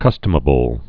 (kŭstə-mə-bəl)